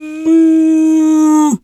pgs/Assets/Audio/Animal_Impersonations/cow_2_moo_01.wav at master
cow_2_moo_01.wav